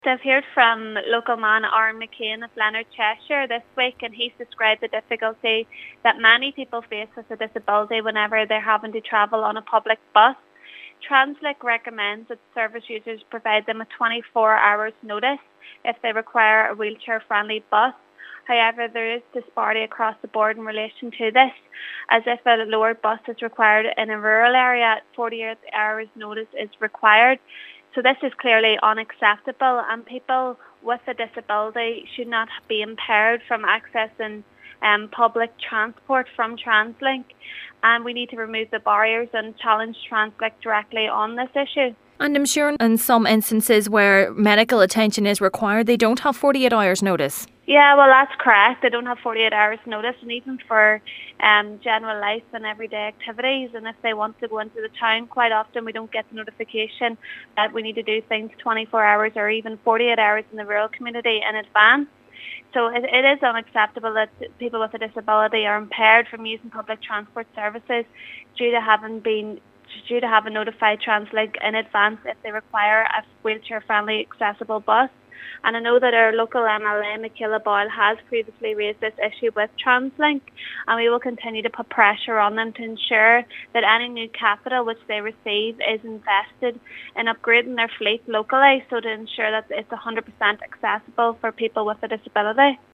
West Tyrone MP Órfhlaith Begley says, having met with a local representative from the Leonard Cheshire Disability charity, it is clear there is a need for policy change: